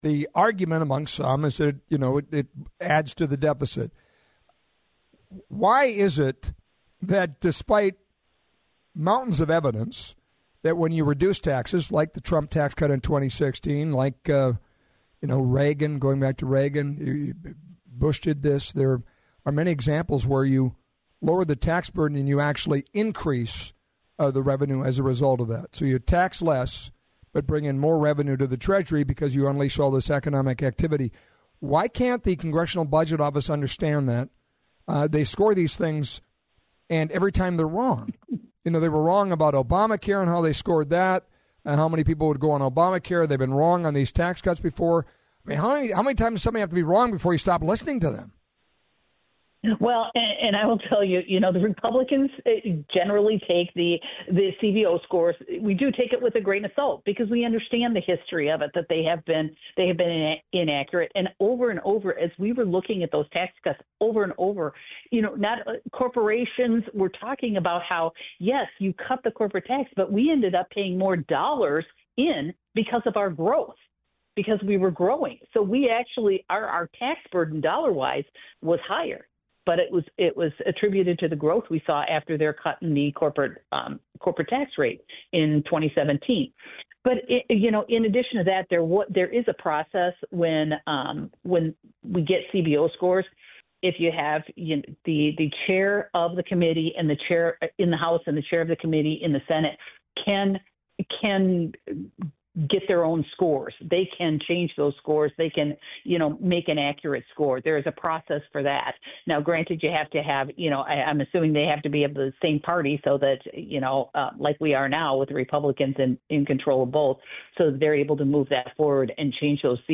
Below: Republican Minnesota Congresswoman Michelle Fischbach talking about the Congressional Budget Office’s estimate on “The Big Beautiful Bill Act”
“Republicans generally take the CBO score with a grain of salt because we understand the history of it,”  Fischbach said on “What’s On Your Mind?” on The Flag.